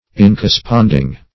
Search Result for " incorresponding" : The Collaborative International Dictionary of English v.0.48: Incorresponding \In*cor`re*spond"ing\, a. Not corresponding; disagreeing.